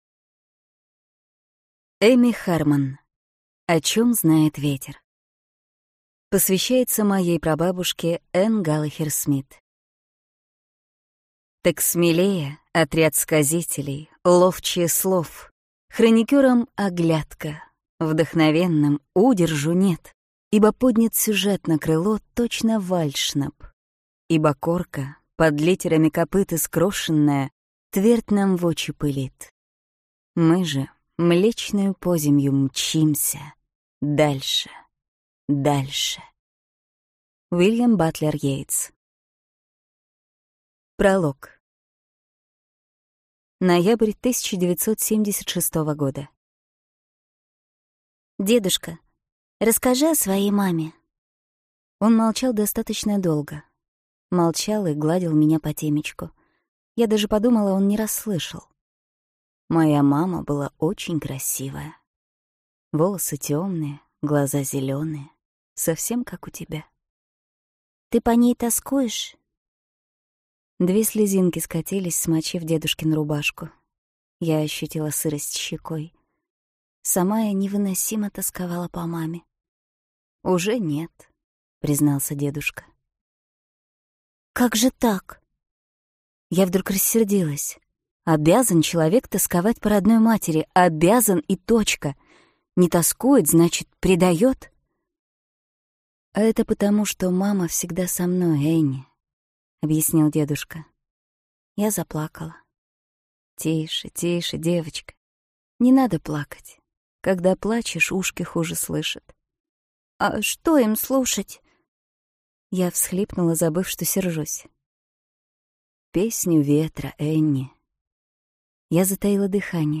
Аудиокнига О чем знает ветер | Библиотека аудиокниг